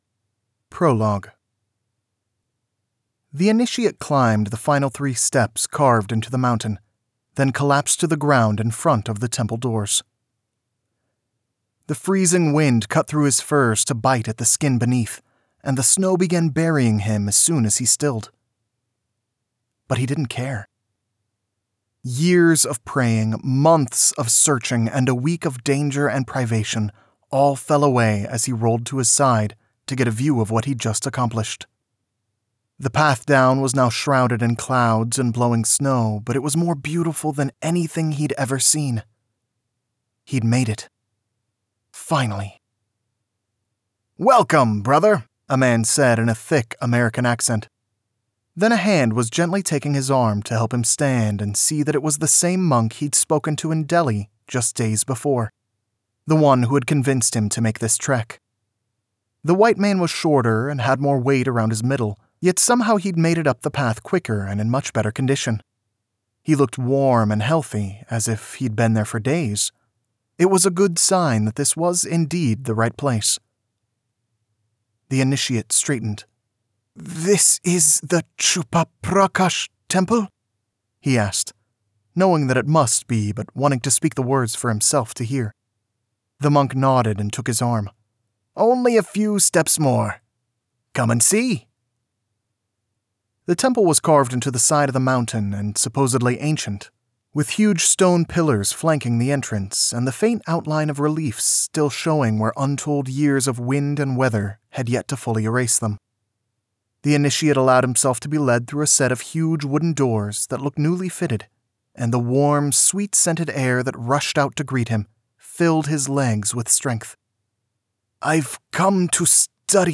Fate Lashed – Audiobook Sample